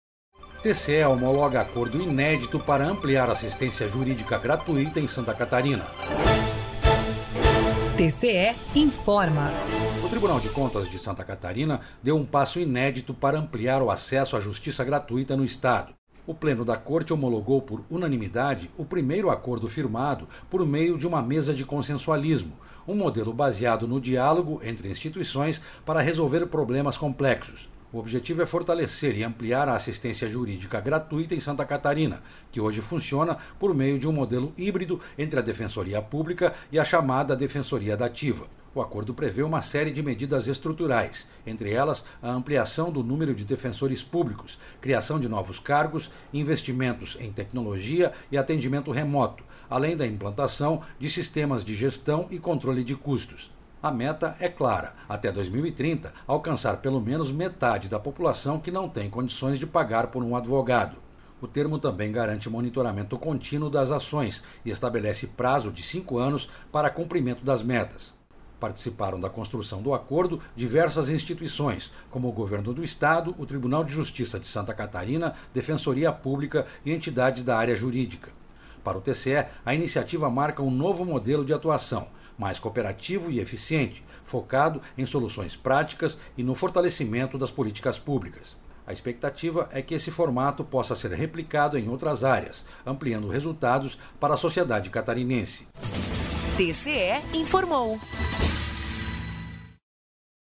Rádio TCE/SC